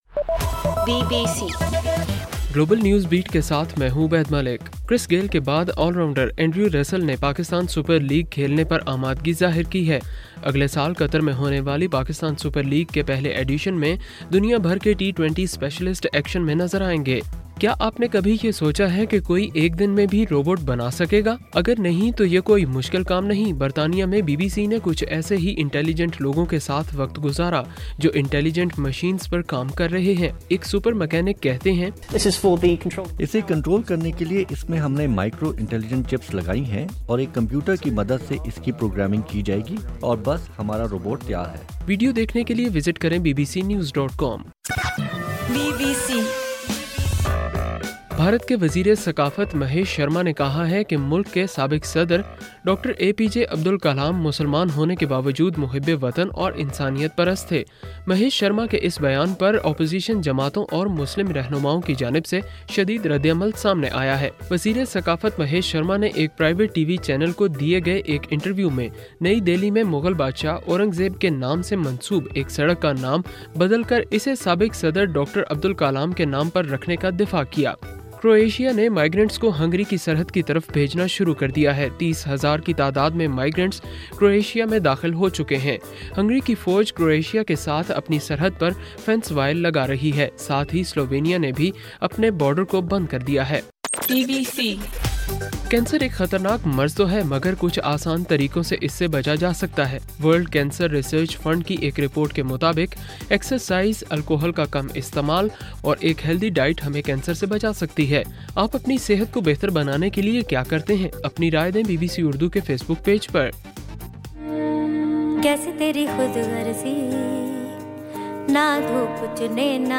ستمبر 18: رات 10 بجے کا گلوبل نیوز بیٹ بُلیٹن